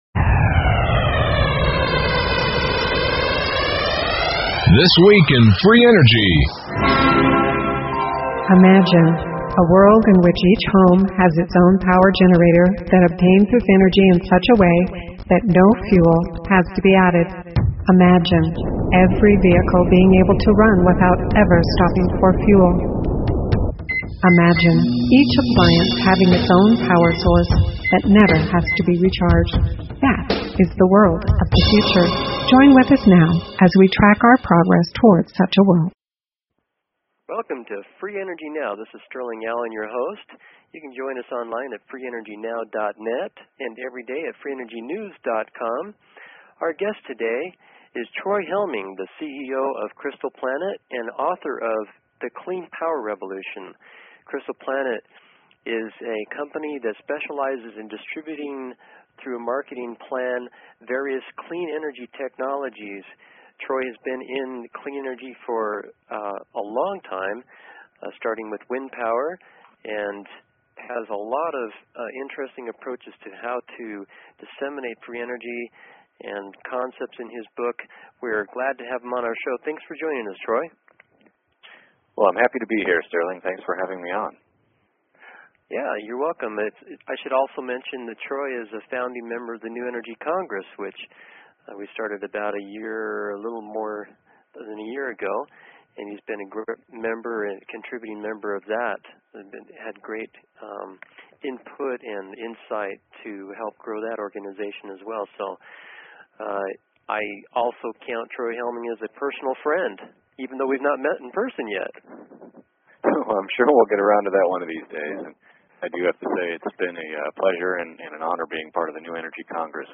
Talk Show Episode, Audio Podcast, Free_Energy_Now and Courtesy of BBS Radio on , show guests , about , categorized as